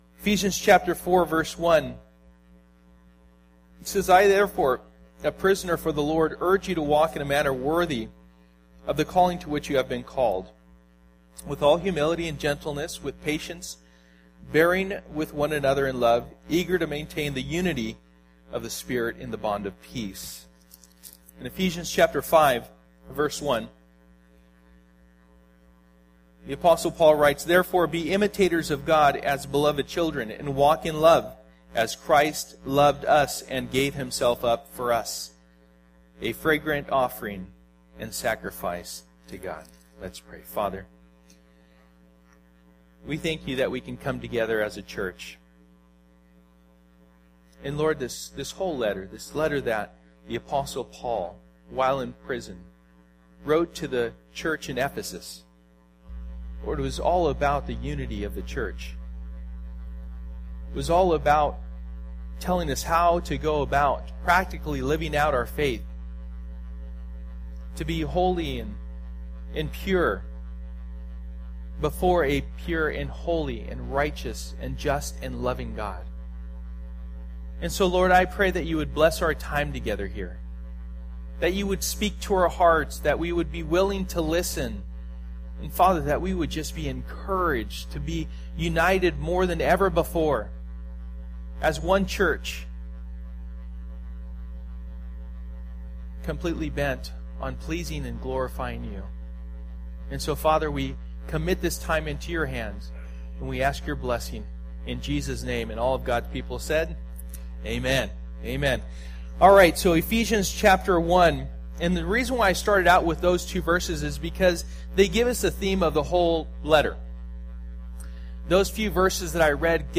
A United Church Passage: Ephesians 1:1-23 Service: Sunday Morning %todo_render% « Stand Fast